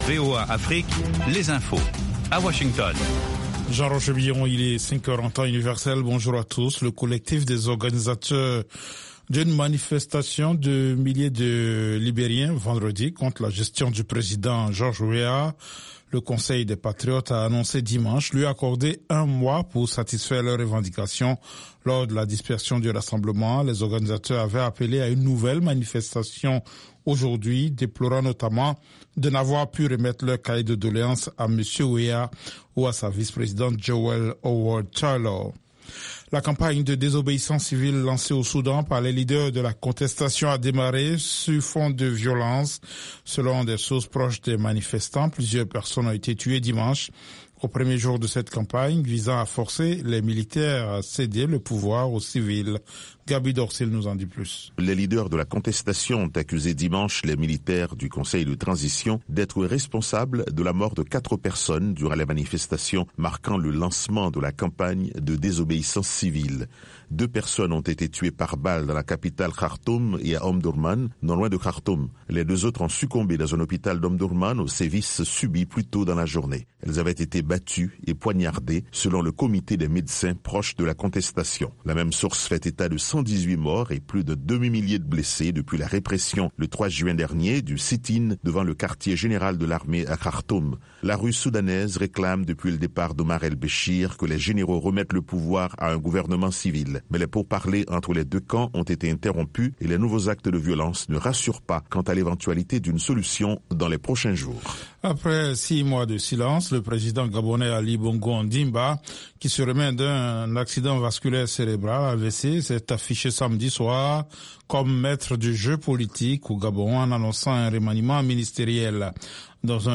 5 min News French